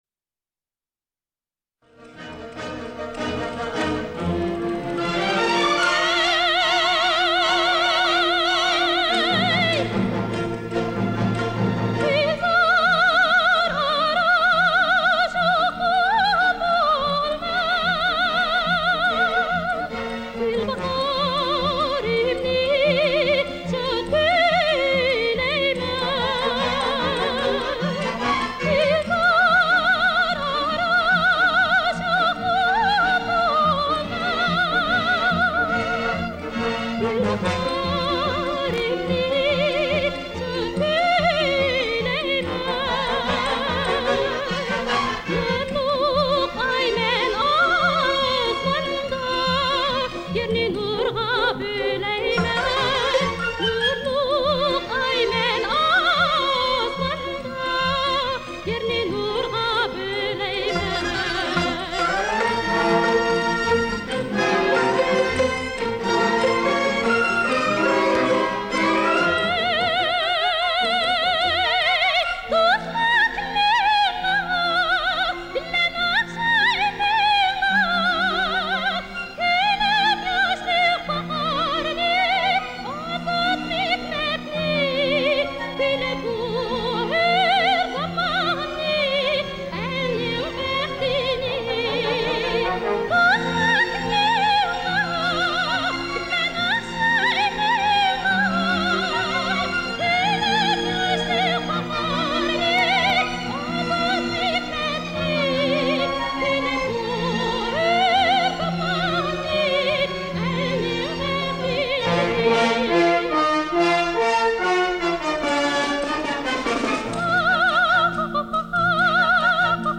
（维语演唱）